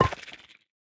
Minecraft Version Minecraft Version latest Latest Release | Latest Snapshot latest / assets / minecraft / sounds / block / sculk_catalyst / place1.ogg Compare With Compare With Latest Release | Latest Snapshot